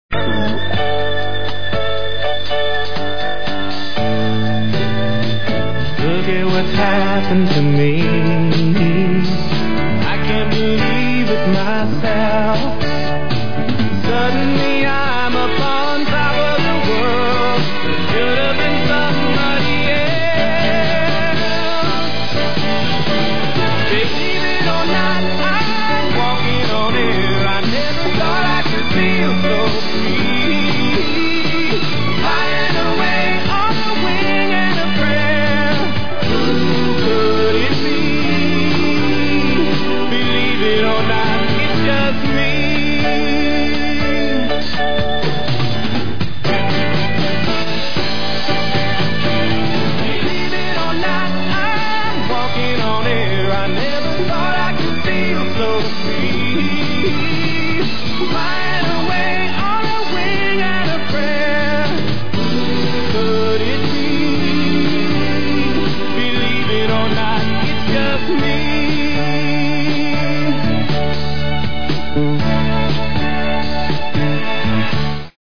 QUI il file WAV della sigla finale originale strumentale